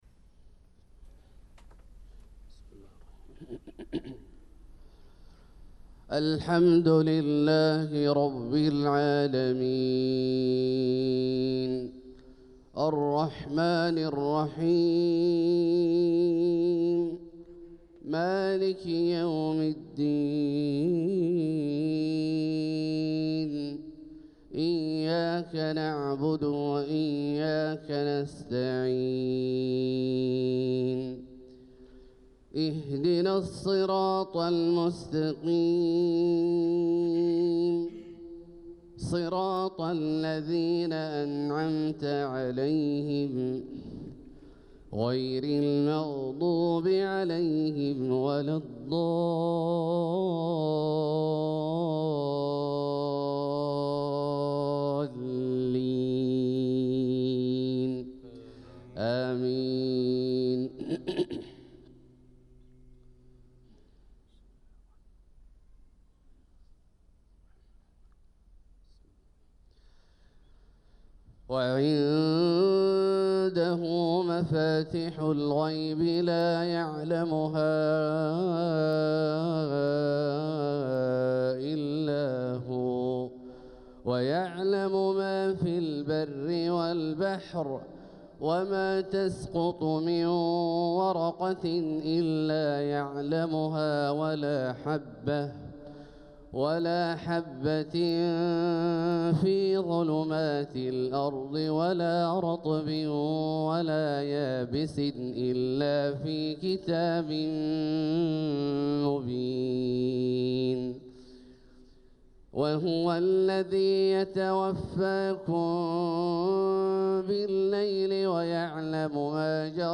صلاة الفجر للقارئ عبدالله الجهني 13 ربيع الآخر 1446 هـ